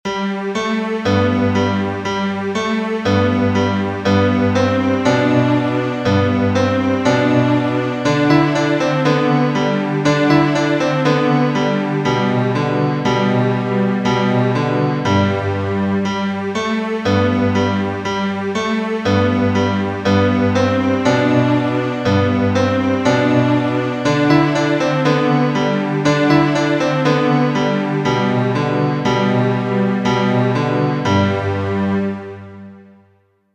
童謡